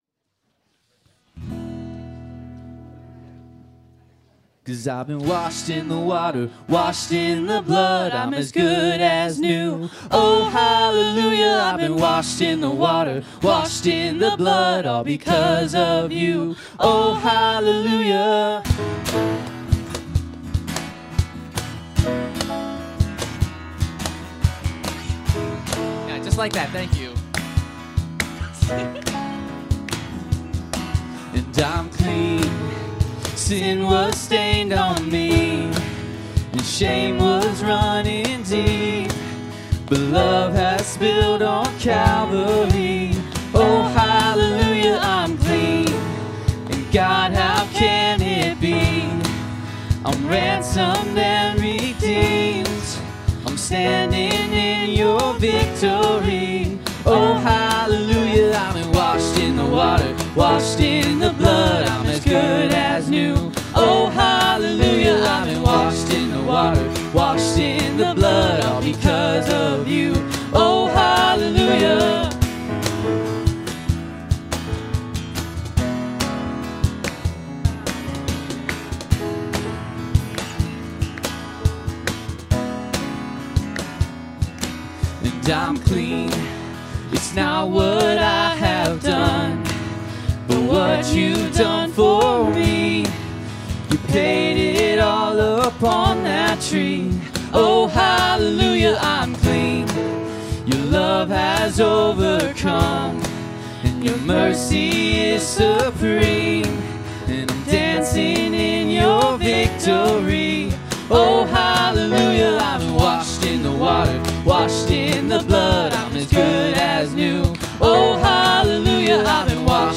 Worship 2026-03-15